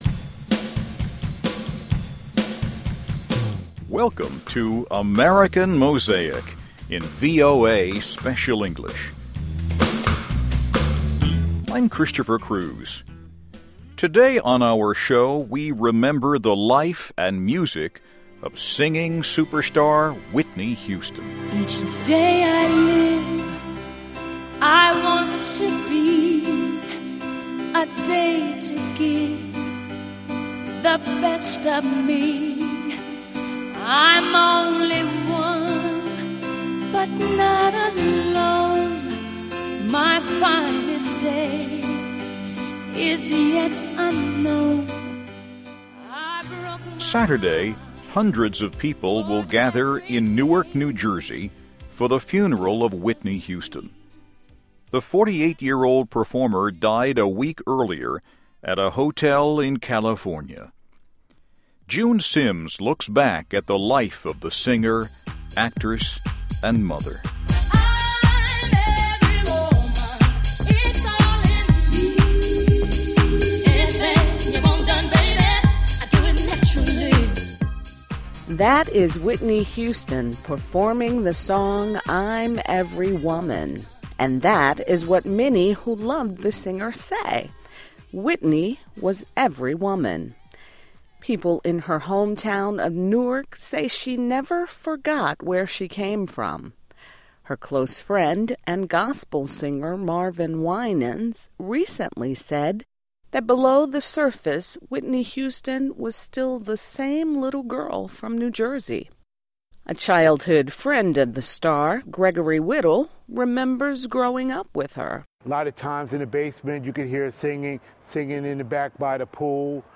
Welcome to AMERICAN MOSAIC in VOA Special English.